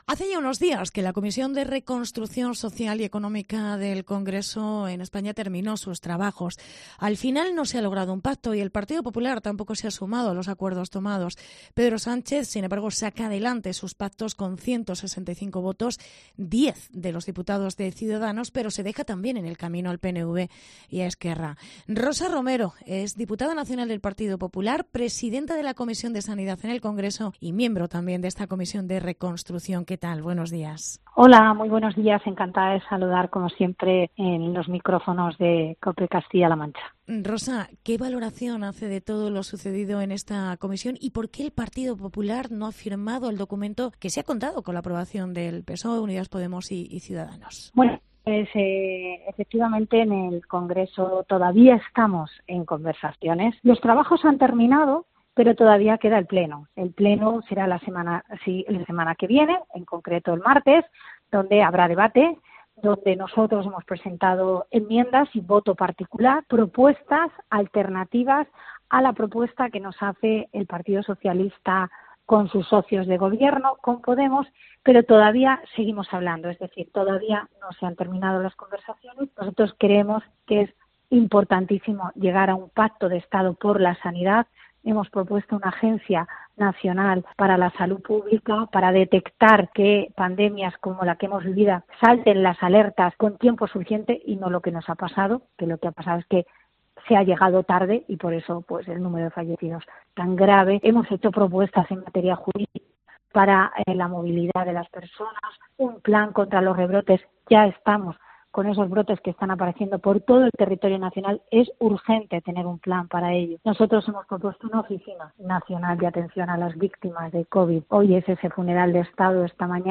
Entrevista con Rosa Romero. Diputada nacional del PP y miembro del la Comisión de Reconstrucción